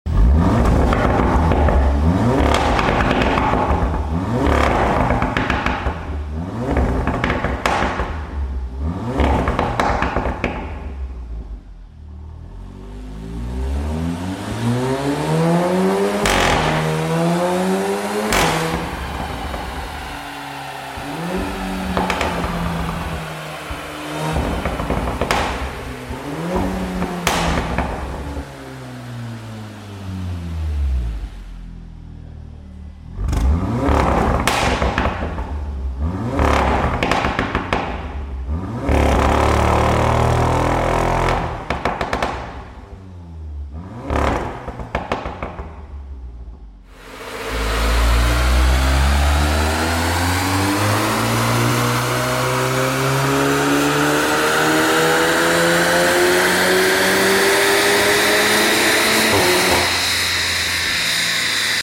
Audi S3 Soundcheck Dynorun Soundpaket sound effects free download
Audi S3 Soundcheck Dynorun Soundpaket Pops and Bangs.